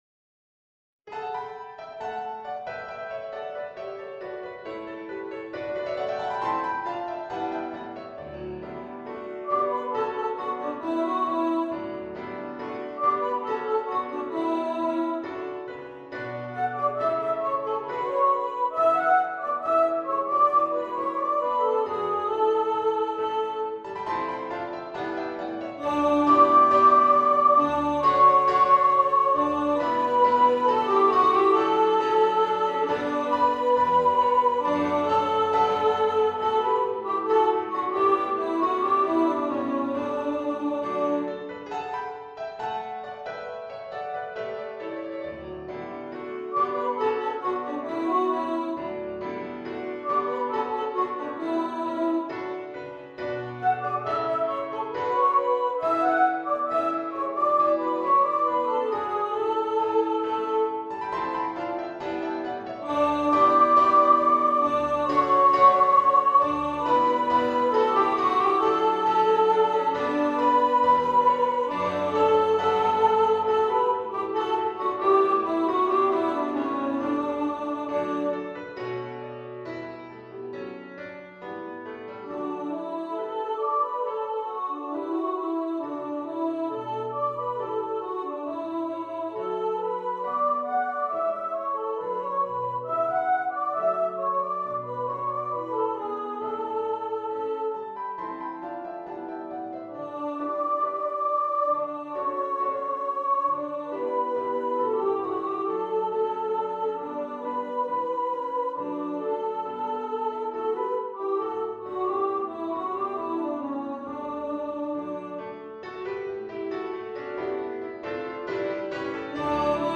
Star-Carol-Soprano.mp3